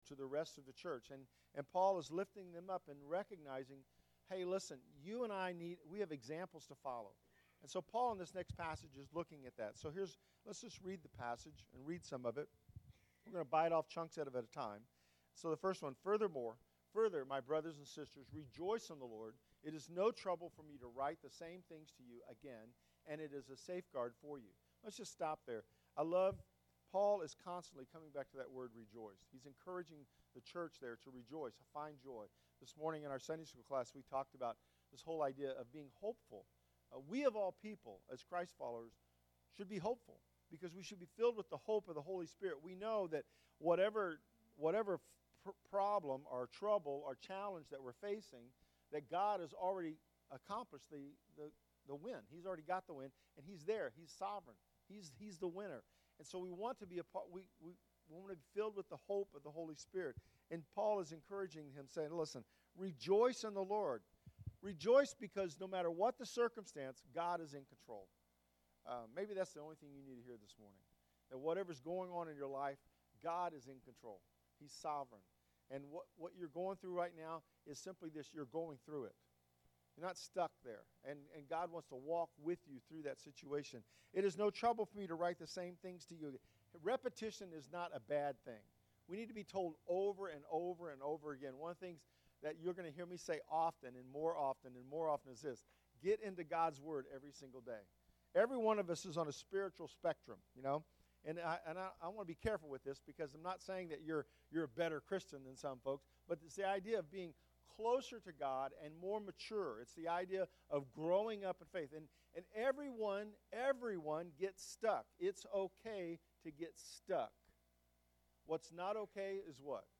I Choose Joy-Preaching Through Philippians – First Baptist Church Oak Grove